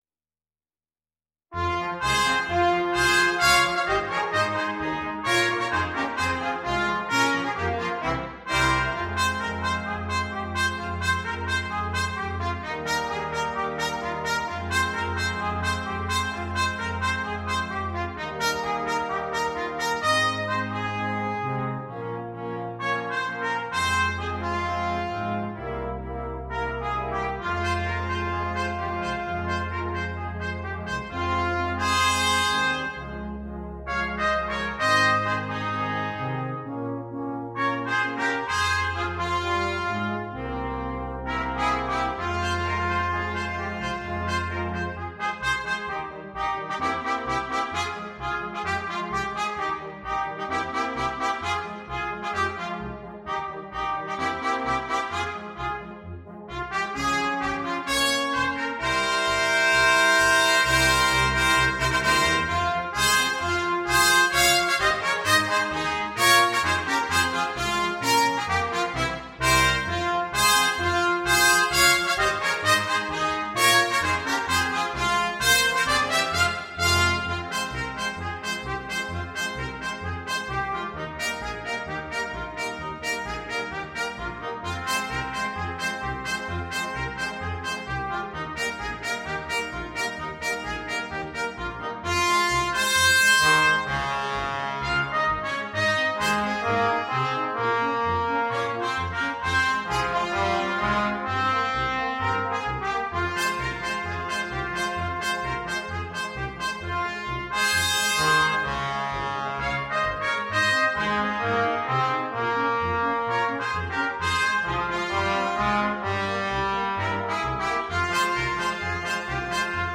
Ноты для брасс-квинтета
Trumpet in B 1, Trumpet in B 2, Horn in F, Trombone, Tuba.